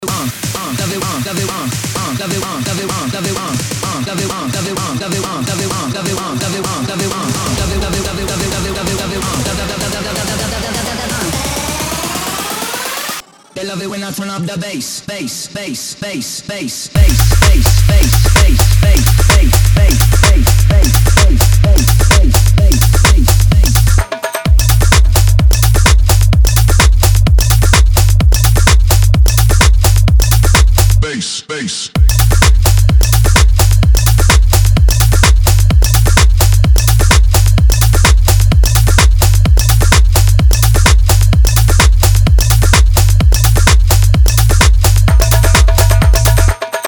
DJ and producer of tech house & house music
His style is unique and electrifying.
DJ